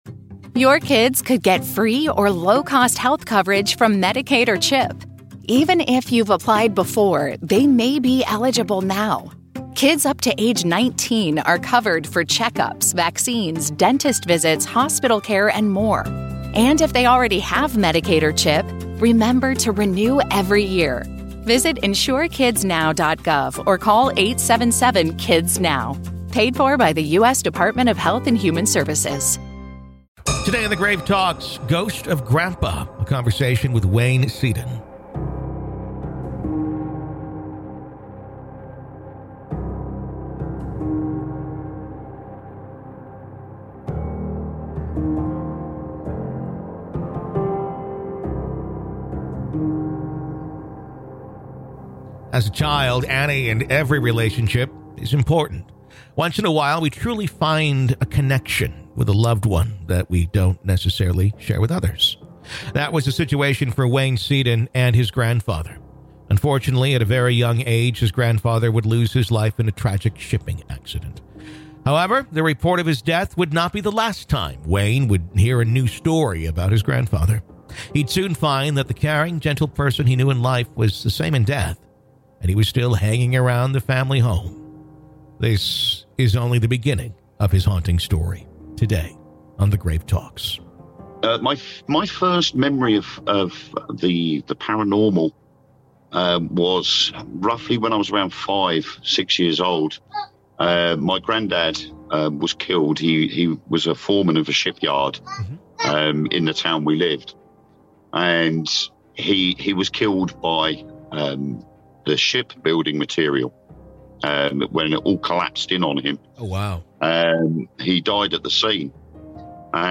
This is only the beginning of his haunting story, today on The Grave Talks If you enjoy our interviews and conversations about "The Dead," why not listen ad-free?